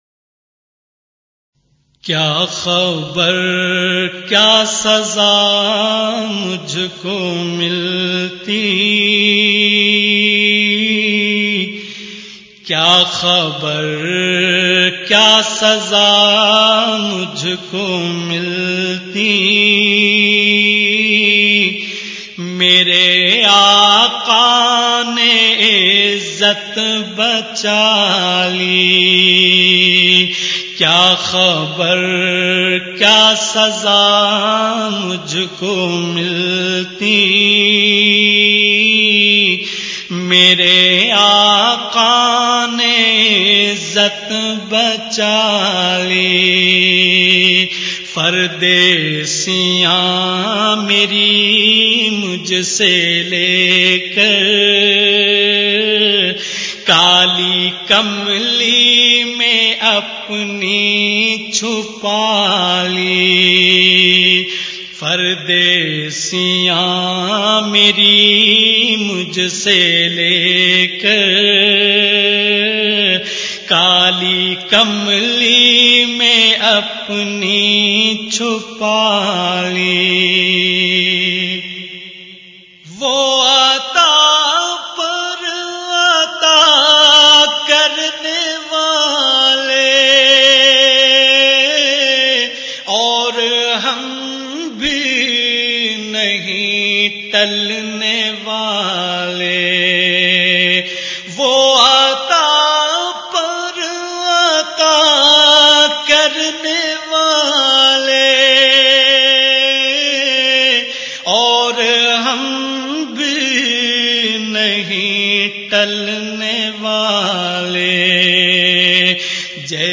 kya khabar kya saza naat